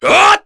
Chase-Vox_Attack3_kr.wav